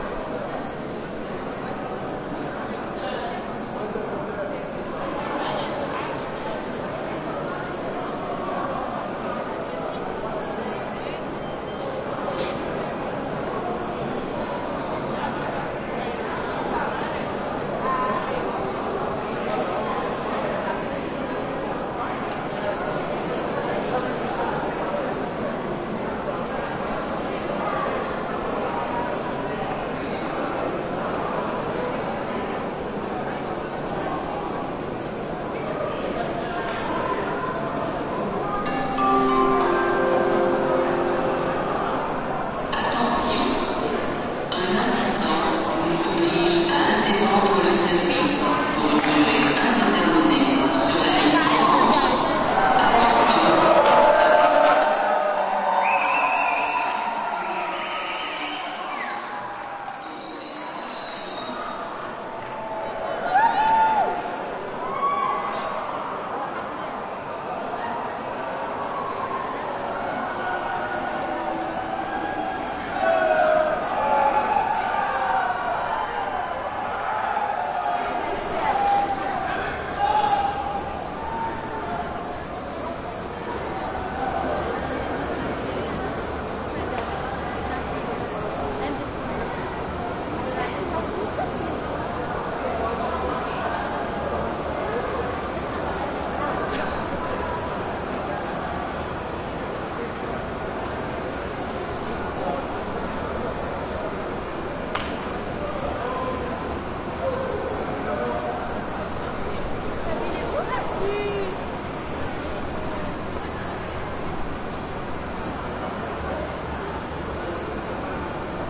蒙特利尔 " 地铁站台轻盈的人群 沉重的通风和嗡嗡声 蒙特利尔，加拿大
描述：地铁地铁平台光人群重型通风和嗡嗡声蒙特利尔，Canada.flac
标签： Montreal hum subway ventilation platform light crowd metro Canada heavy
声道立体声